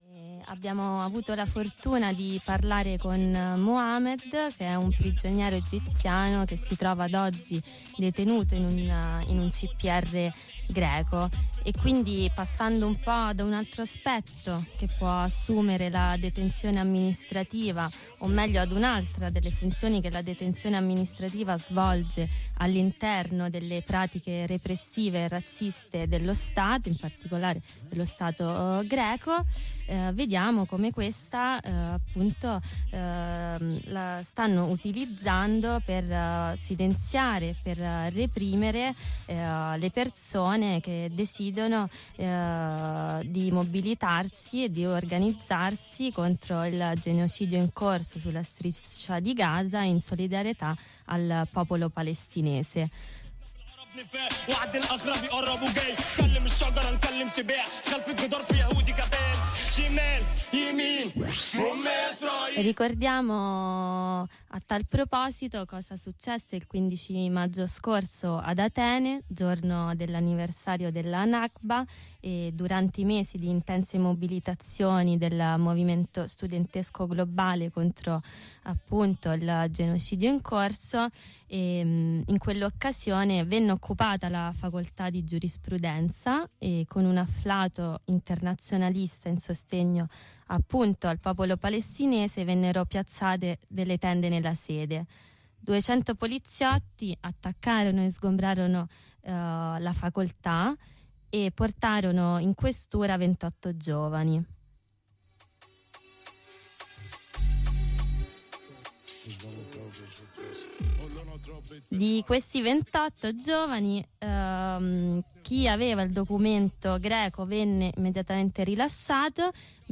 Ai microfoni di Harraga – trasmissione in onda su Radio Blackout ogni venerdì dalle 14.30 alle 16 – abbiamo parlato dell’utilizzo che lo Stato Greco sta facendo della detenzione amministrativa per colpire il movimento di solidarietà alla Palestina e reprimendo più duramente chi è oppresso lungo le linee di classe e razza.